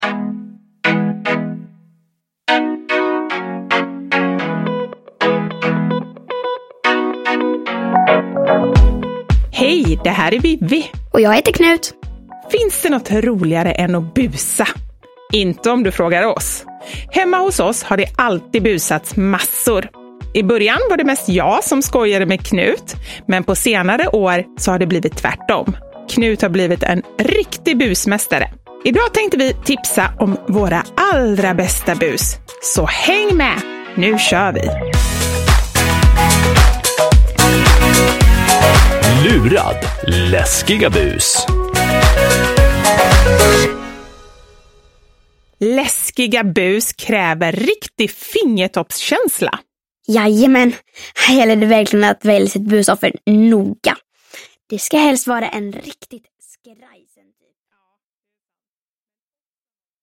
Lurad! Läskiga bus : 59 roliga bus och pranks – Ljudbok – Laddas ner